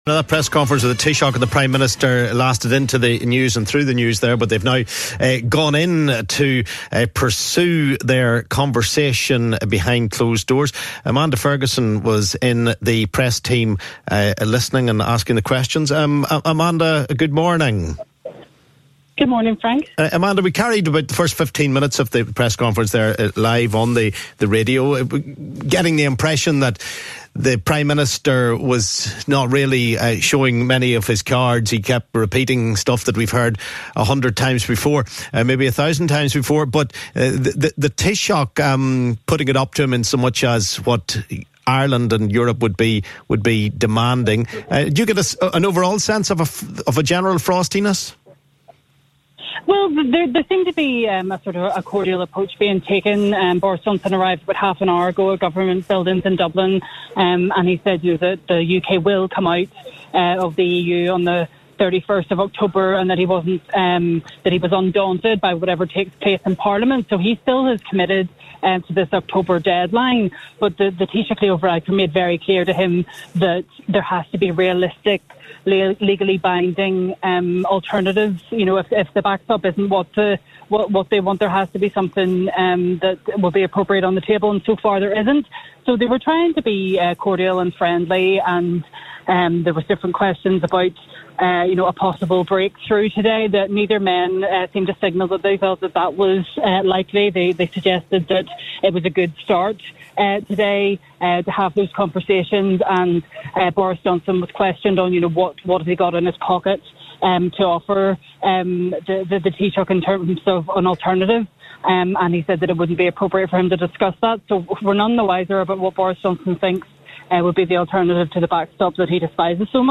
LISTEN¦ Ahead of their meeting, PM Boris Johnson and Taoiseach Leo Varadkar speak to the press.